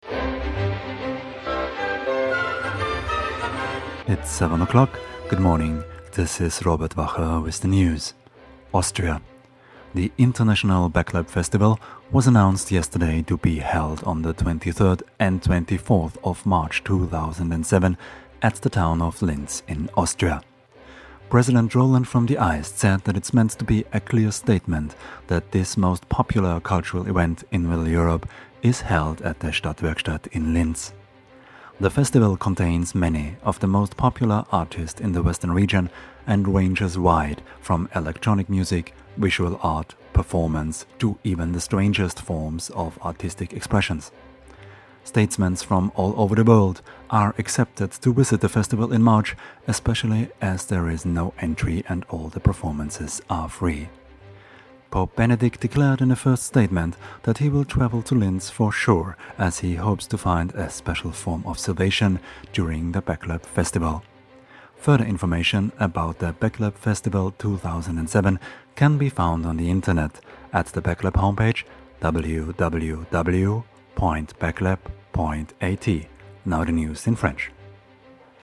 Festival Jingle 1